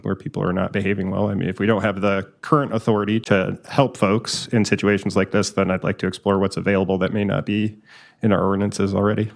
Councilmember Chris Burns proposed possibly looking into a “chronic nuisance” ordinance to handle situations like this.